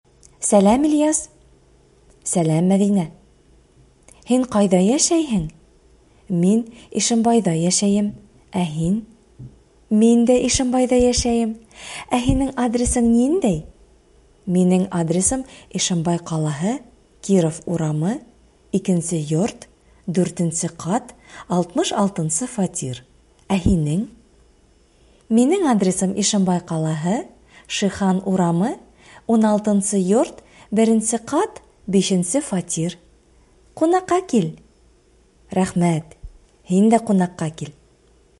Диалог 1